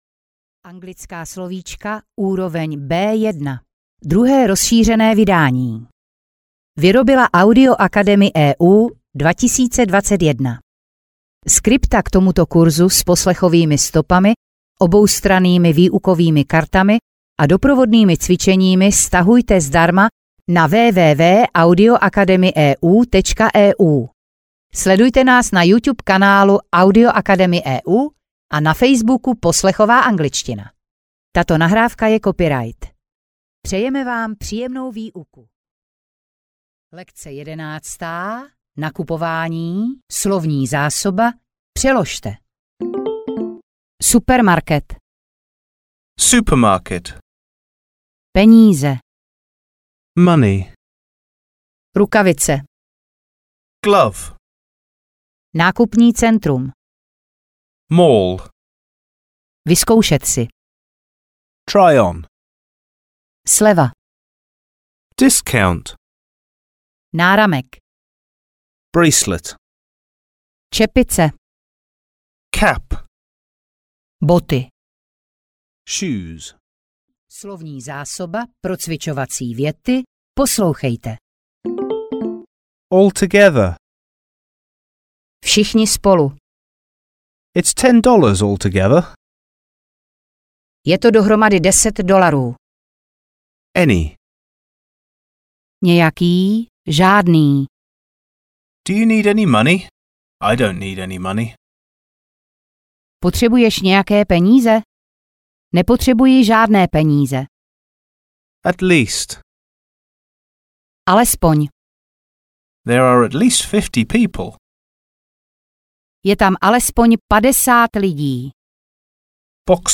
Audio knihaAngličtina - slovíčka pro mírně pokročilé B1